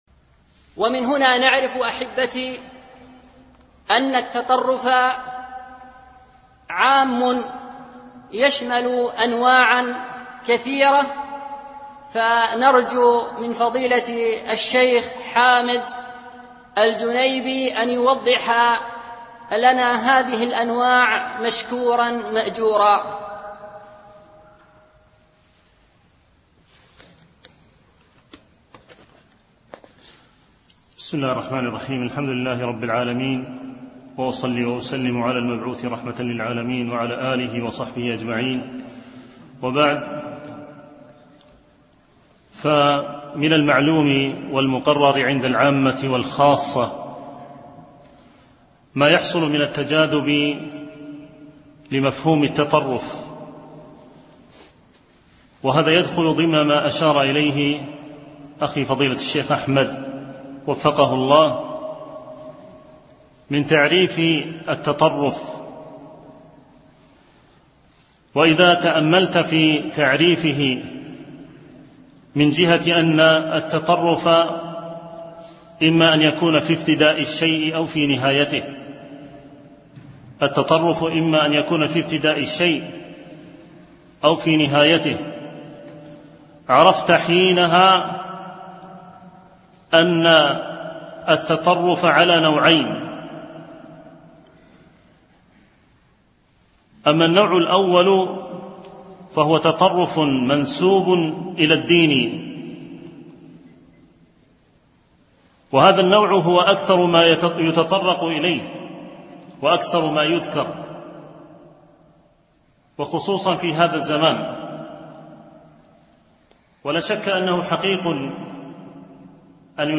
dw-imam-malik-4-min-nadwat-atataruf_002.mp3